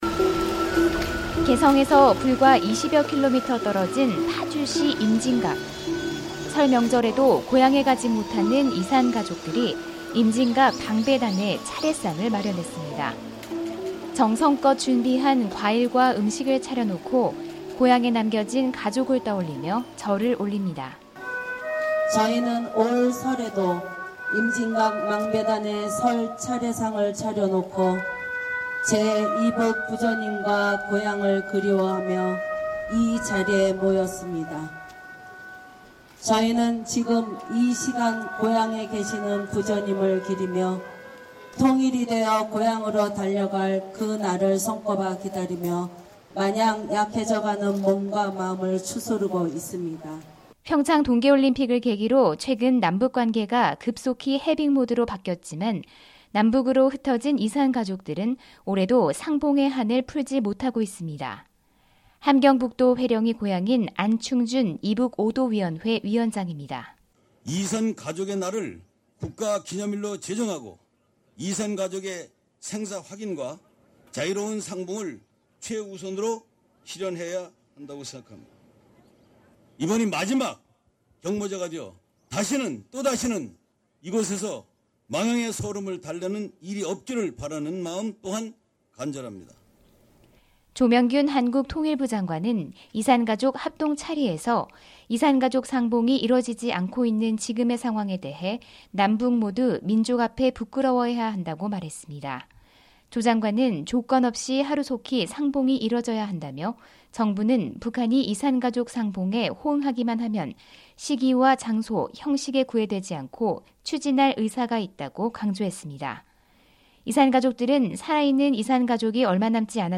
[특파원 리포트 오디오] 한국 내 이산가족들, 설 맞아 임진각서 합동 차례